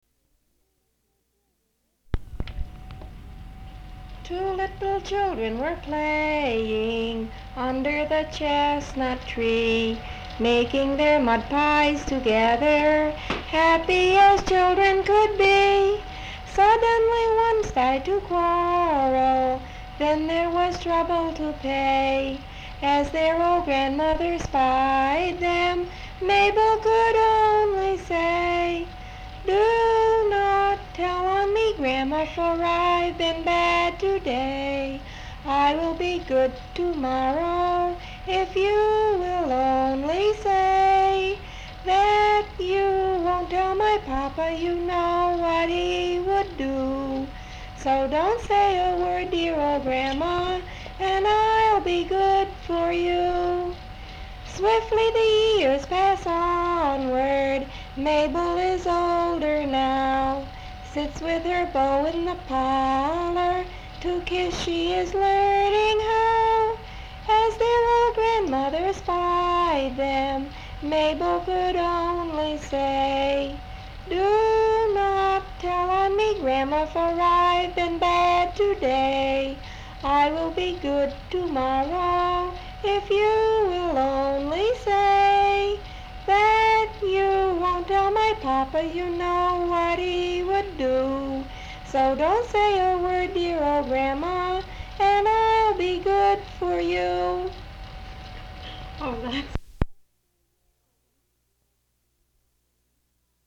Folk songs, English--Vermont (LCSH)
sound tape reel (analog)
Location Marlboro, Vermont